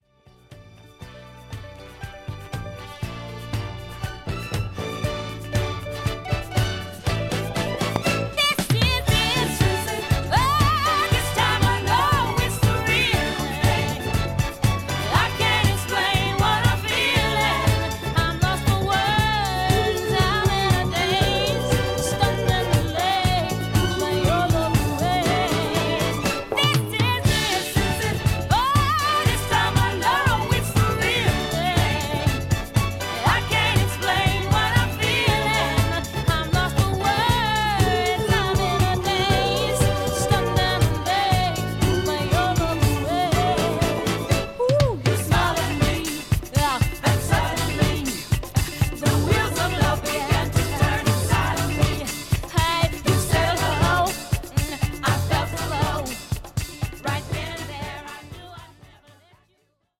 モータウン・ソウルのような溌剌さも感じさせる良ディスコ・ダンサーです。